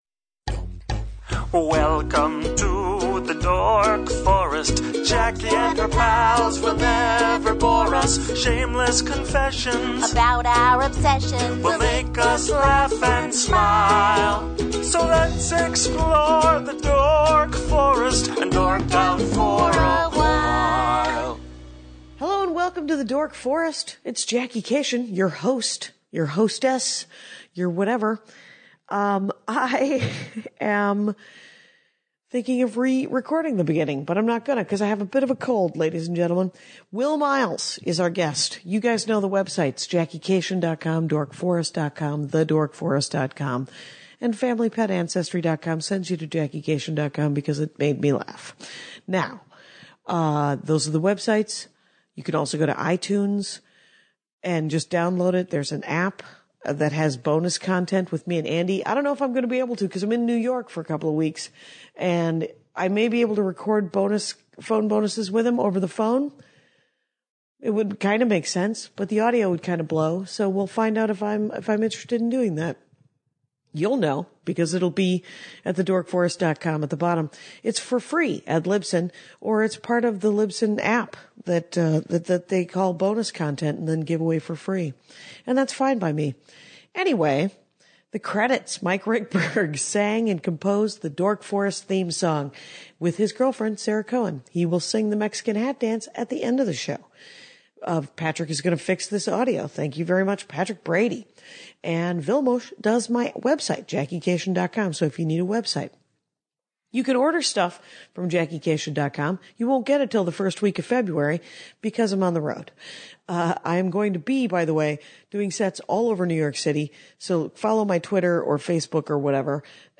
She reads HER OWN AD.